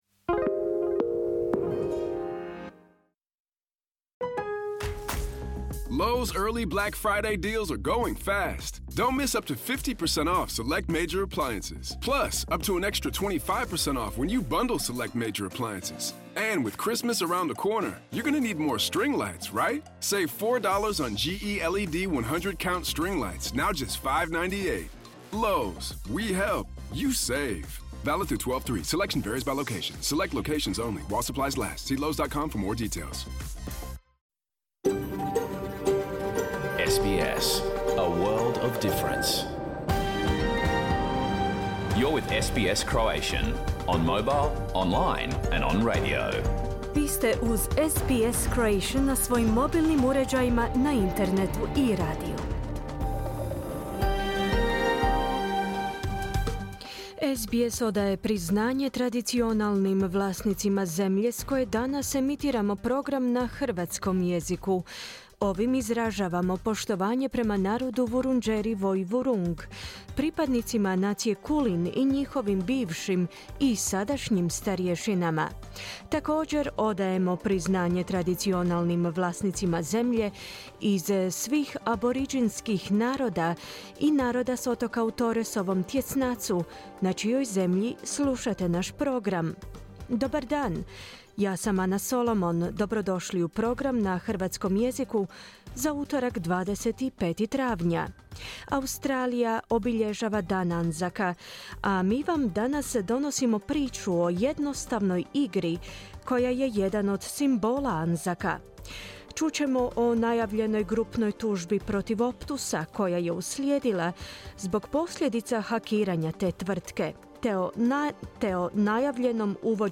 Pregled vijesti i aktualnih tema iz Australije, Hrvatske i ostatka svijeta. Program je emitiran uživo u utorak, 25. travnja u terminu od 11 do 12 sati.